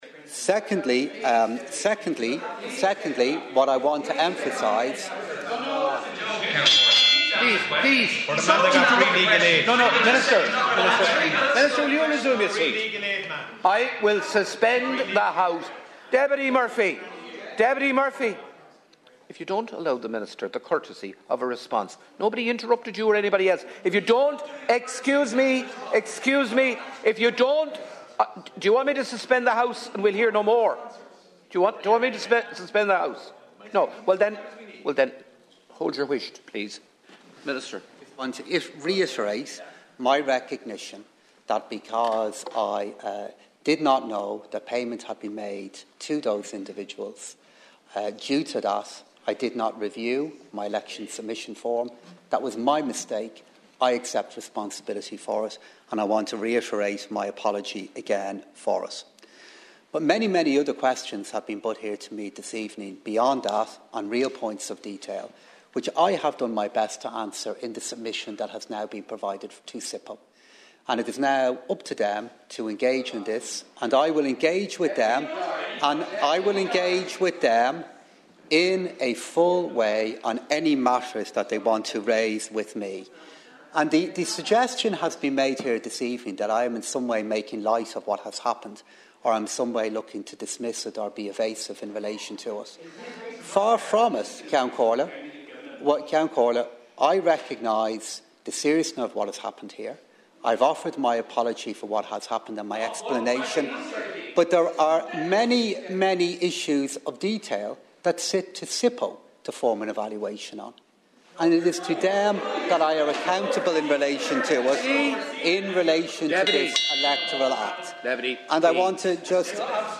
There was much ruckus in the Dail this evening following a statement from Minister Paschal Donohoe in relation to the incorrect filing of election expenses.
As Minister Donohoe attempted to respond to questions raised by Deputies, he was interrupted by interjections from Donegal Deputies Padraig MacLochlainn and Pearse Doherty.